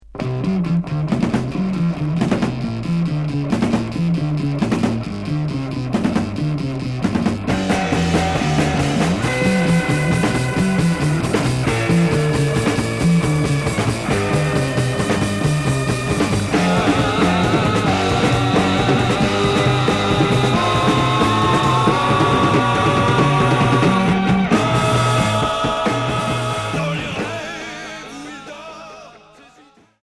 Heavy rock Deuxième 45t retour à l'accueil